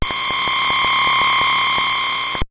moongate_flash.ogg